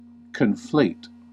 Ääntäminen
IPA : /kənˈfleɪt/